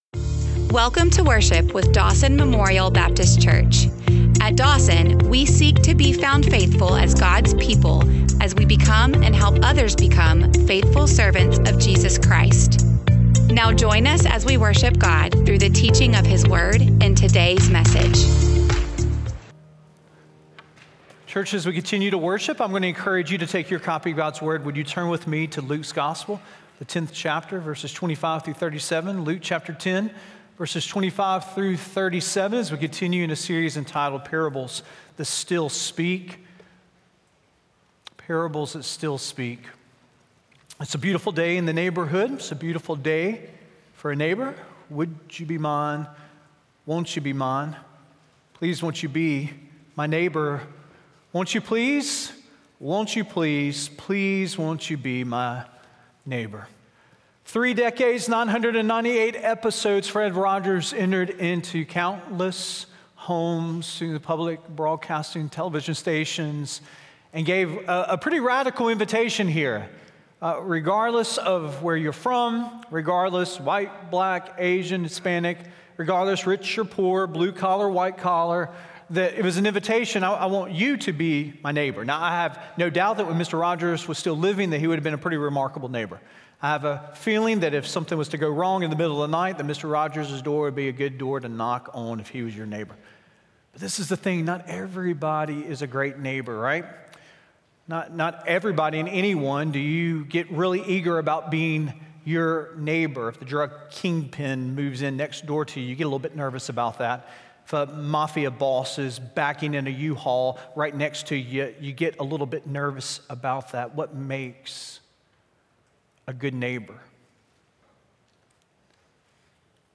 SermonAudio11225.mp3